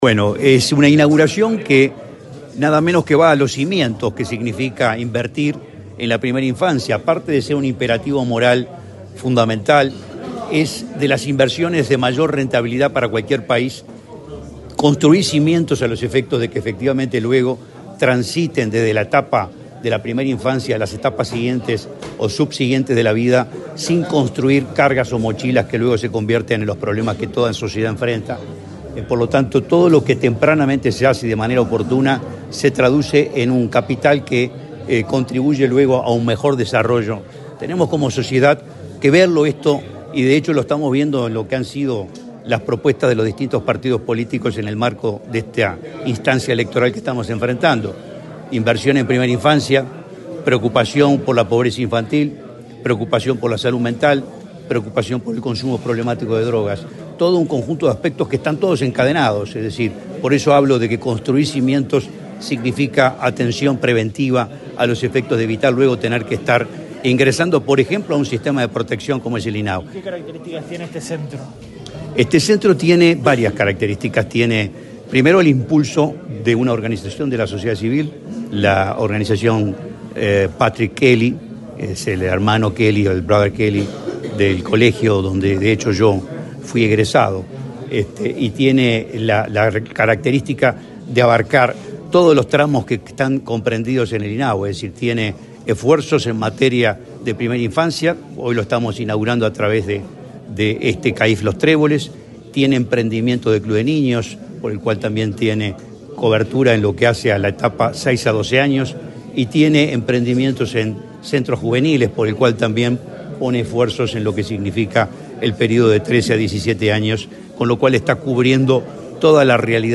Declaraciones del presidente de INAU, Guillermo Fosatti
Declaraciones del presidente de INAU, Guillermo Fosatti 23/08/2024 Compartir Facebook X Copiar enlace WhatsApp LinkedIn Este viernes 23, el presidente del Instituto del Niño y el Adolescente del Uruguay (INAU), Guillermo Fosatti, dialogó con la prensa, antes de participar en la inauguración del centro de atención a la infancia y la familia (CAIF) Los Tréboles, en Montevideo.